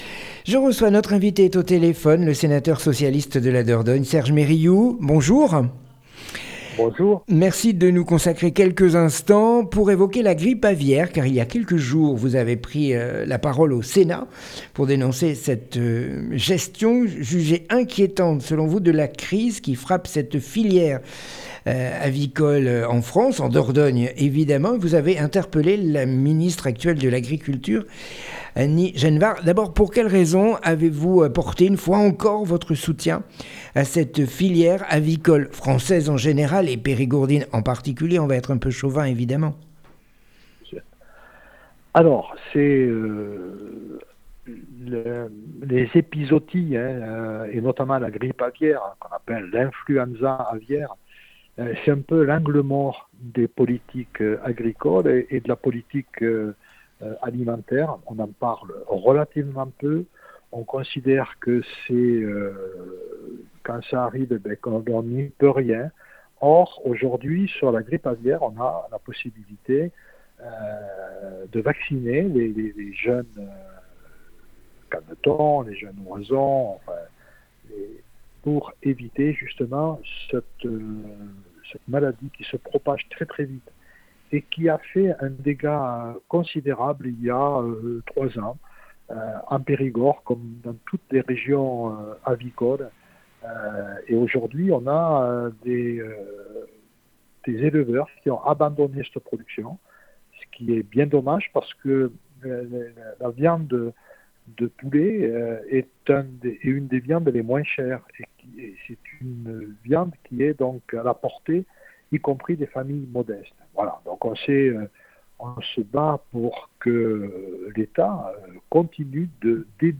Le sénateur socialiste de DORDOGNE s'est exprimé au sénat face à la prolifération de la grippe aviaire